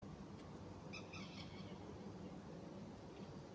камышница, Gallinula chloropus
Примечанияbalss naktī.